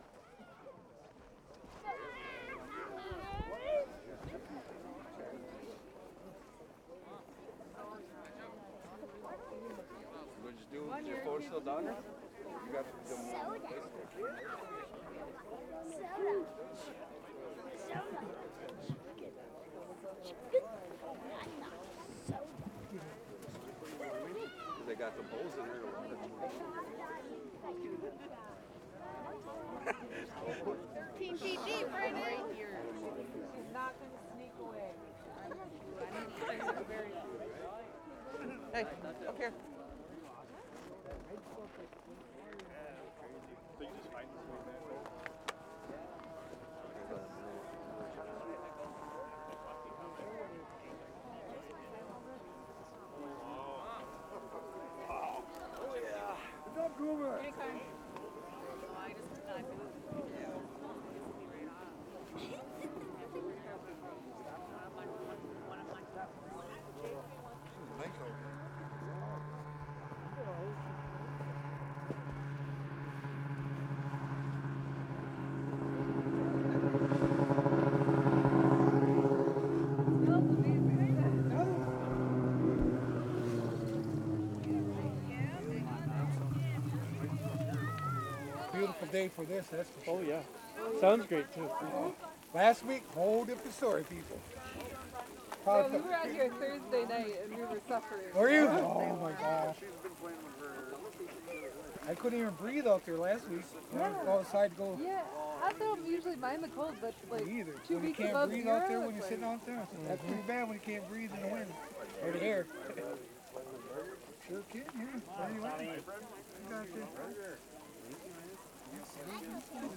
Index of /SkyCloud/Audio_Post_Production/Education/The Ways/2026-01-29 Ice Fishing Camp - Mole Lake/Day 3 - 1-31